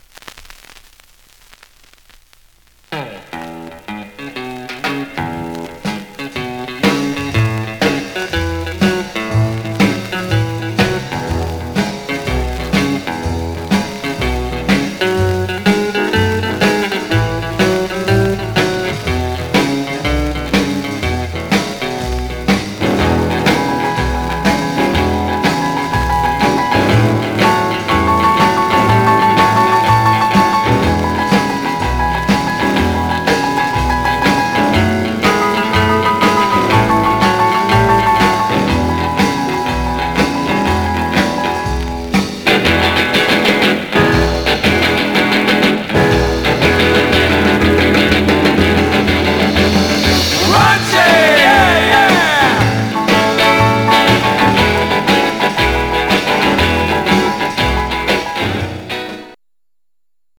Surface noise/wear
Mono
R & R Instrumental Condition